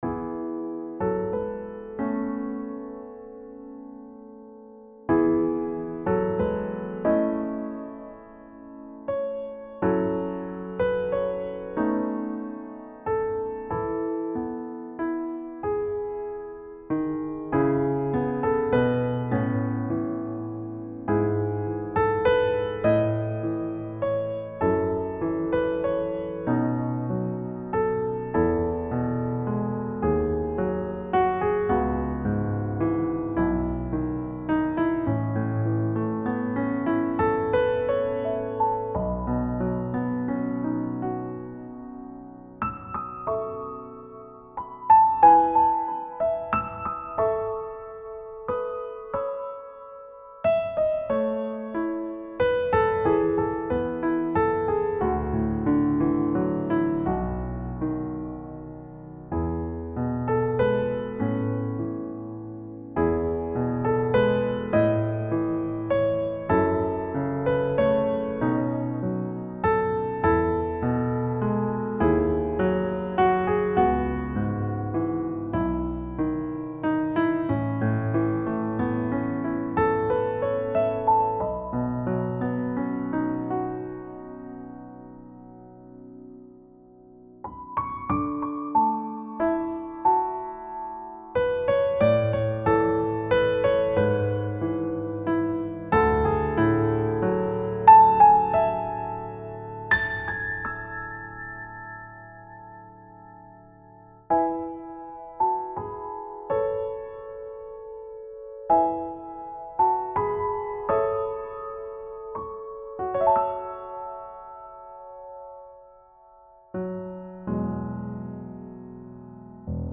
• Key: E Major
• Time signature: 3/4